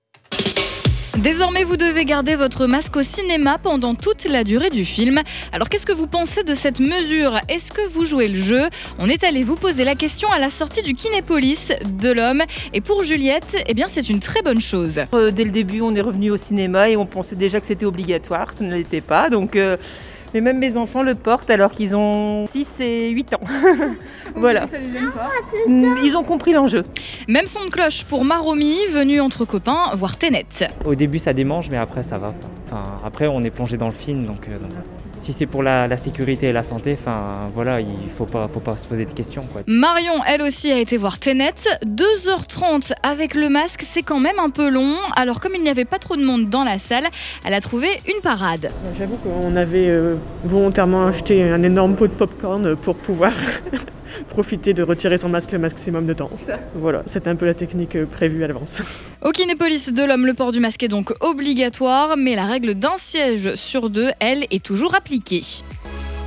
Vos réactions à la sortie du Kinépolis de Lomme :